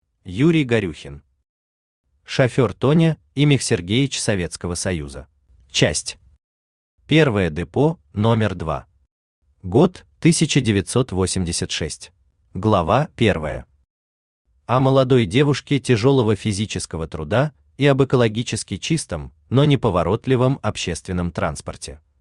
Aудиокнига Шофёр Тоня и Михсергеич Советского Союза Автор Юрий Горюхин Читает аудиокнигу Авточтец ЛитРес.